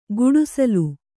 ♪ guḍusalu